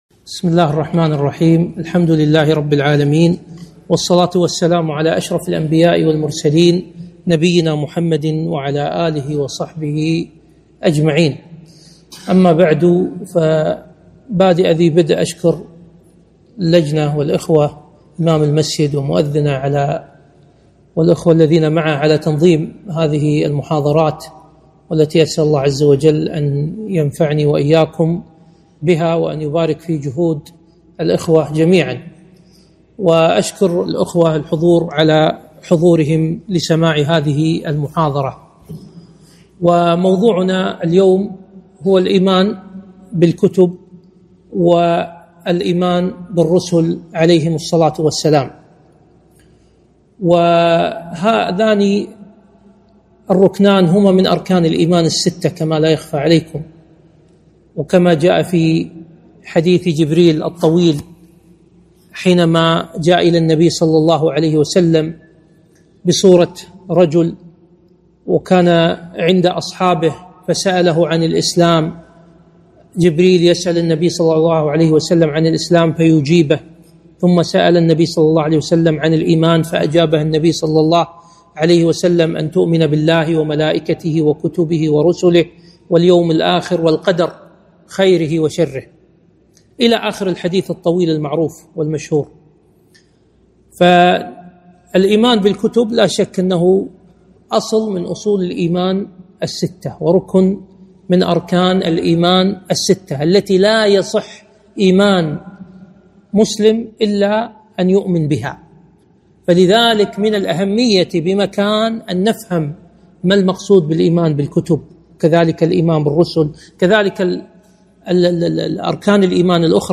محاضرة - الإيمان بالكتب